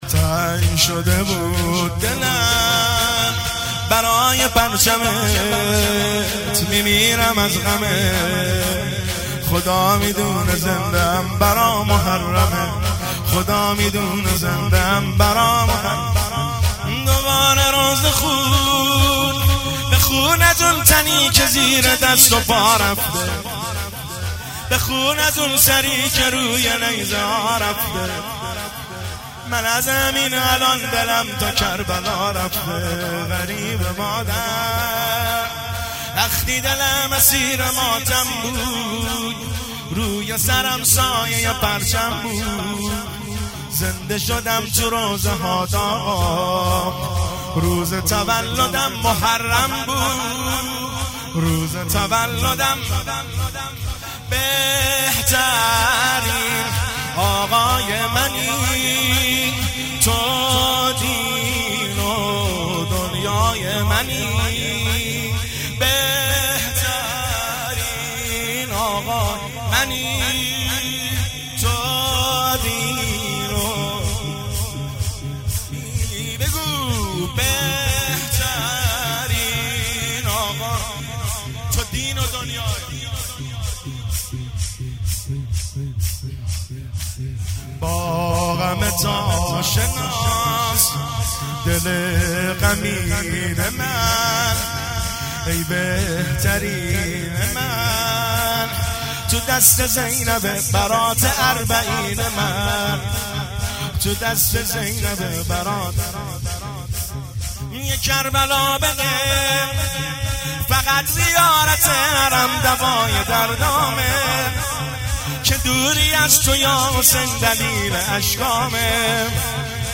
مداحی جدید
شب اول محرم 1400
شور